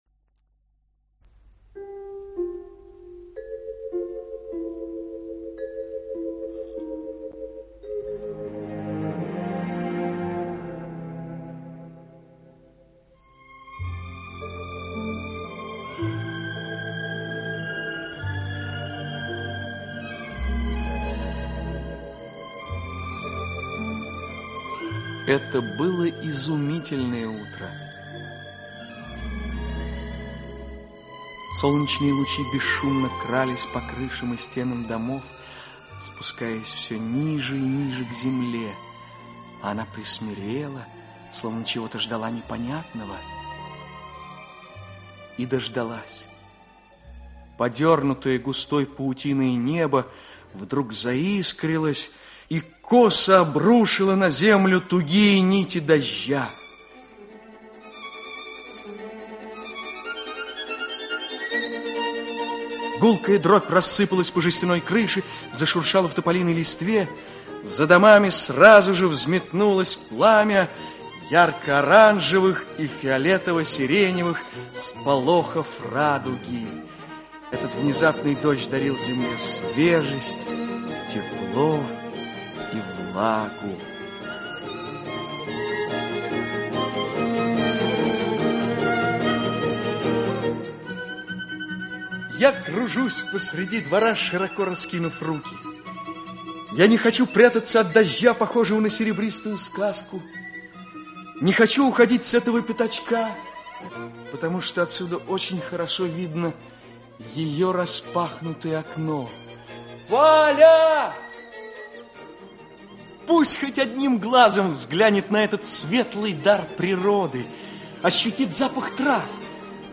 Aудиокнига Много – мало Автор Аркадий Федорович Пинчук Читает аудиокнигу Олег Табаков.